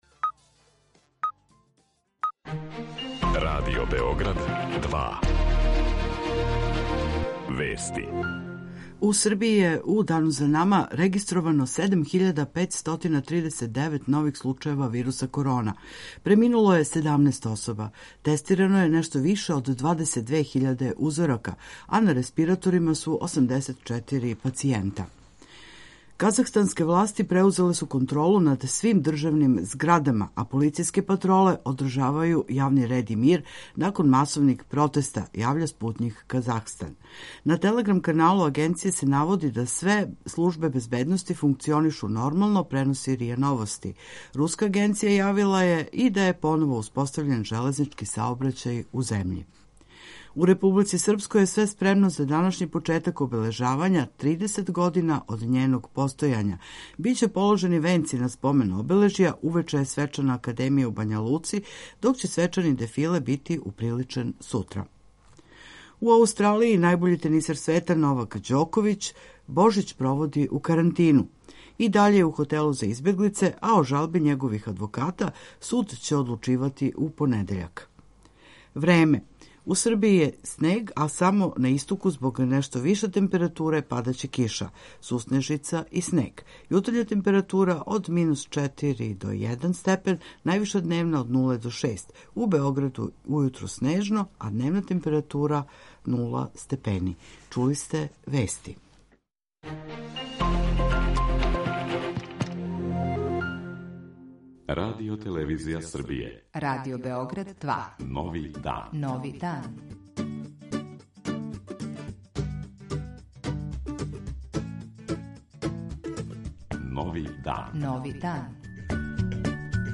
Друге редовне рубрике и сервисне информације.